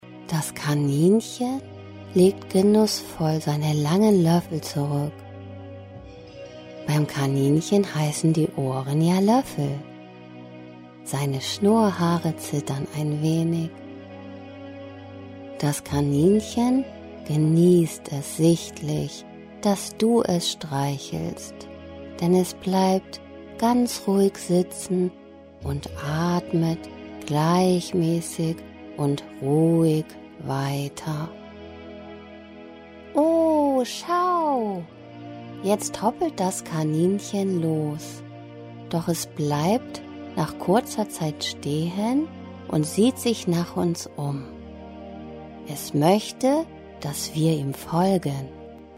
Traummusik spielt währenddessen im Hintergrund und hilft beim Weiterträumen nach dem Ende der Traumreise.